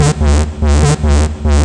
BL 145-BPM 3-D.wav